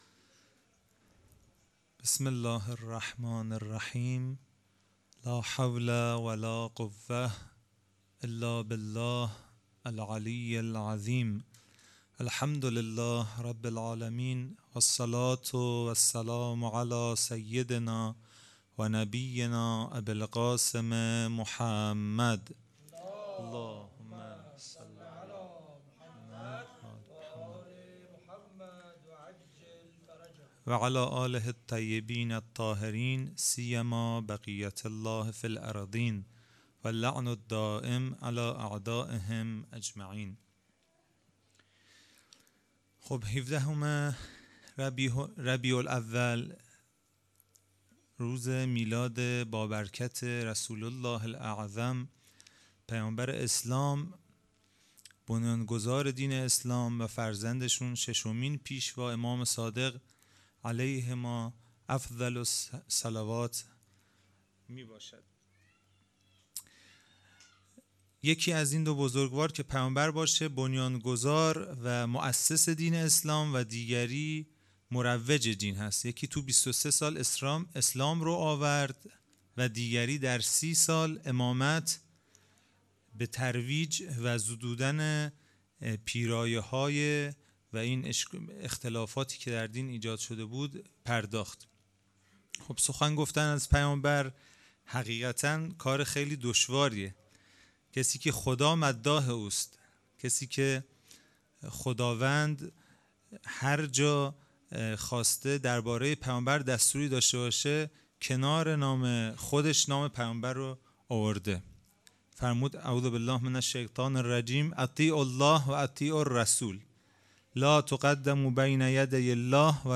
هیئت حسن جان(ع) اهواز
شب دوم جشن میلاد حضرت محمد (ص) و امام صادق (ع) 1401